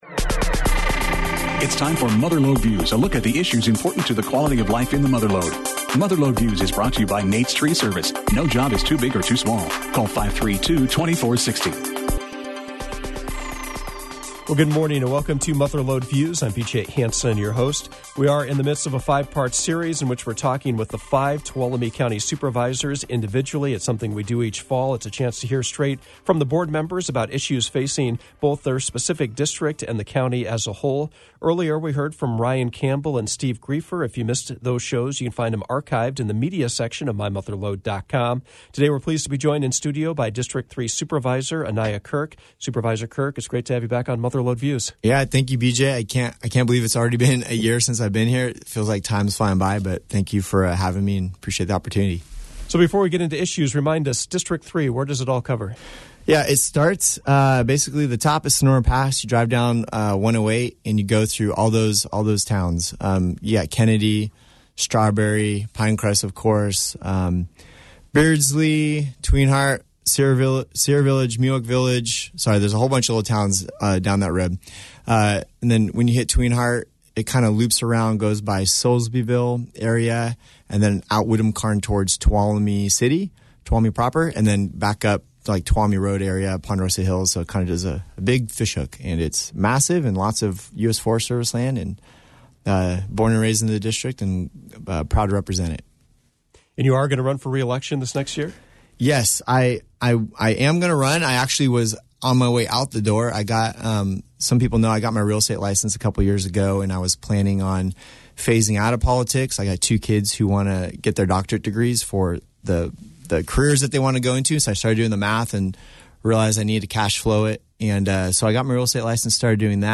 Mother Lode Views featured the third installment of our series interviewing all five members of the Tuolumne County Board of Supervisors.